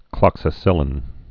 (klŏksə-sĭlĭn)